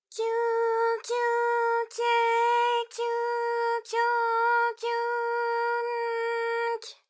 ALYS-DB-001-JPN - First Japanese UTAU vocal library of ALYS.
kyu_kyu_kye_kyu_kyo_kyu_ky.wav